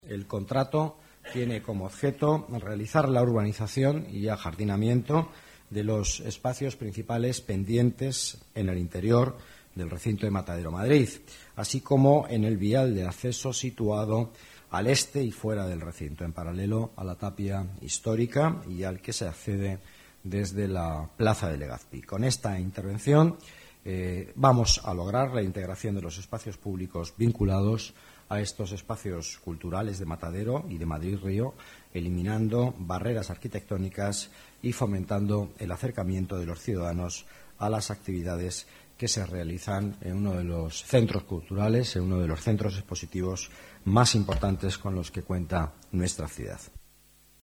Nueva ventana:Declaraciones vicealcalde, Miguel Ángel Villanueva: obras urbanización en Matadero Madrid